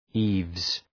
Προφορά
{i:vz}